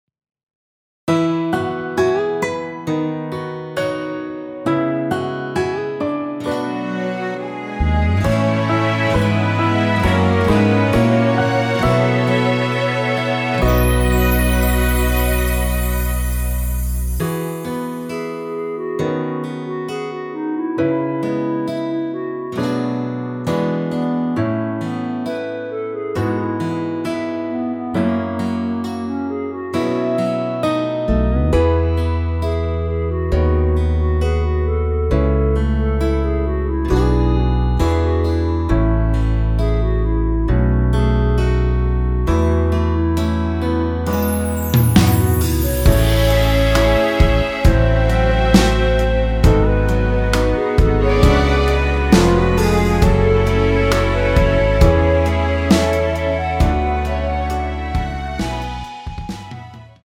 1절 앞 ‘그렇게 그렇게 그저 살아~’ 다음 중간 간주 다음 나오는’사는 게 힘겨워서~’로 진행 되며
원키에서(+1)올린 (1절앞+후렴)으로 진행되는 멜로디 포함된 MR입니다.(미리듣기 확인)
앞부분30초, 뒷부분30초씩 편집해서 올려 드리고 있습니다.
중간에 음이 끈어지고 다시 나오는 이유는